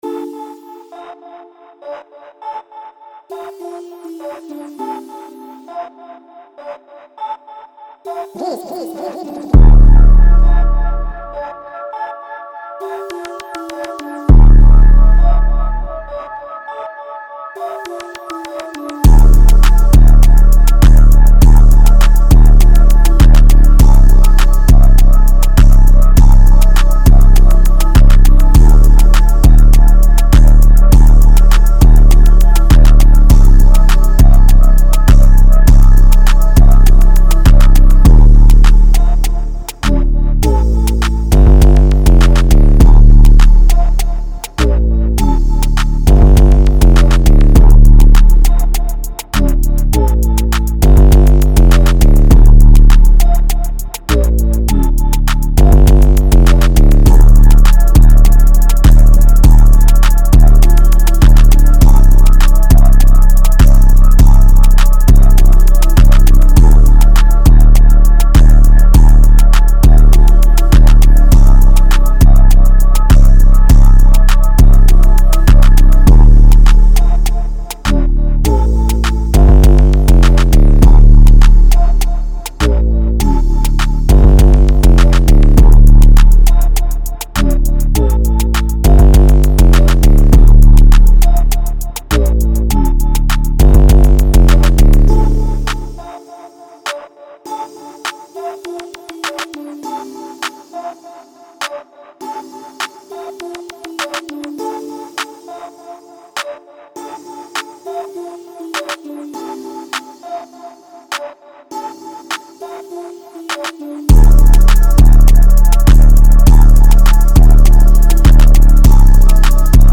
Hip-hop Энергичный
Бас